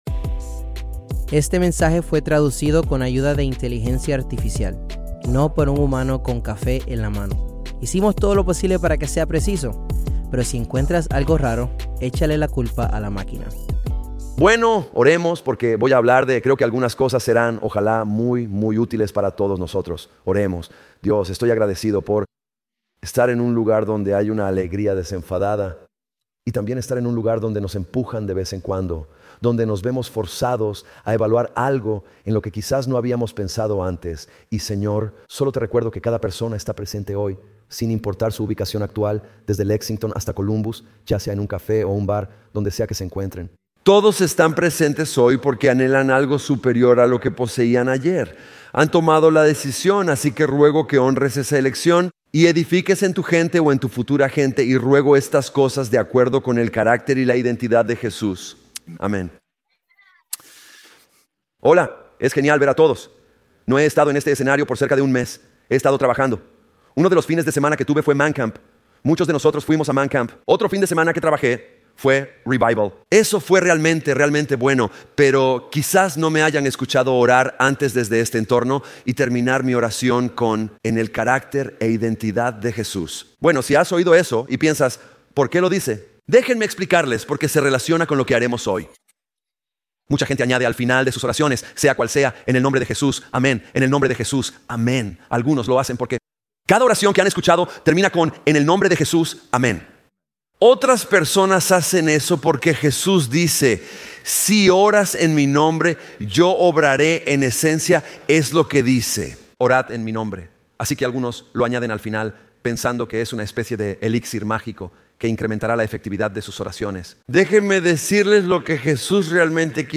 Grabado en vivo en Crossroads Church en Cincinnati, Ohio.